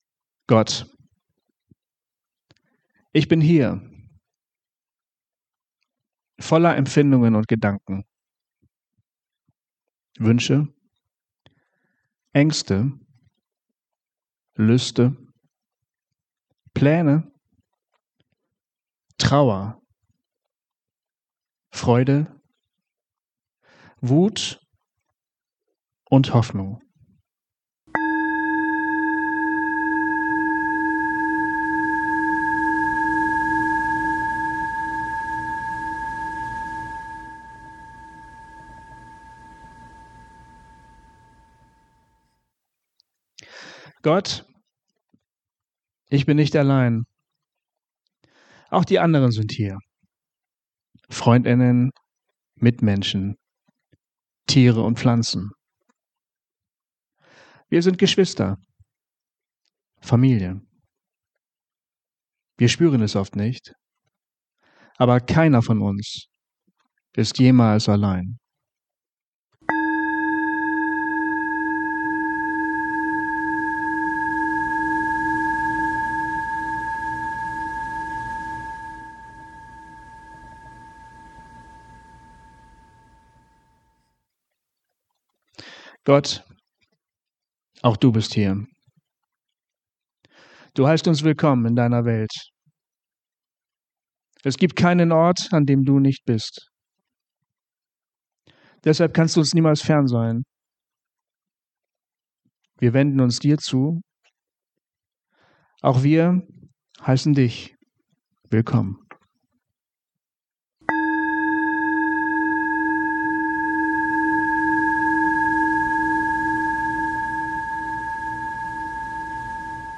Gott, ich bin hier – Meditation zum Beginn:
(beginnt mit einer kurzen Stille zum Ankommen)